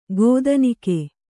♪ gōdanike